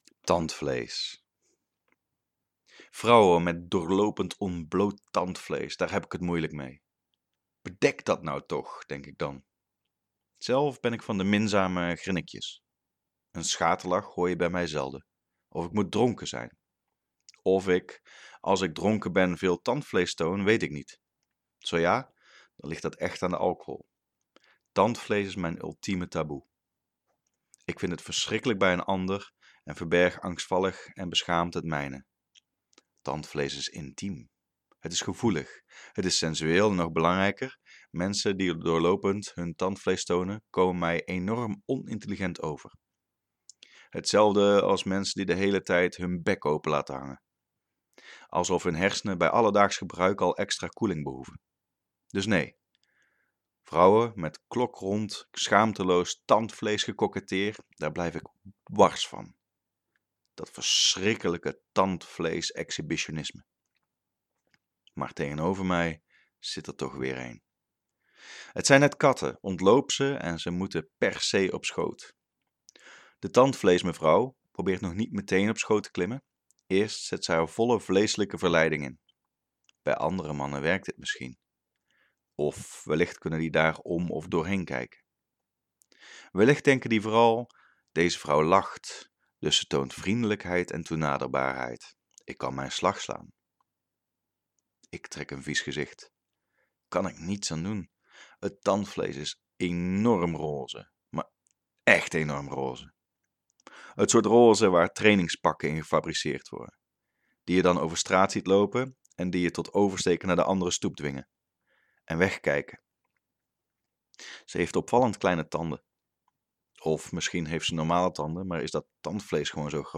Audio stories Korte proza